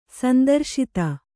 ♪ sandarśita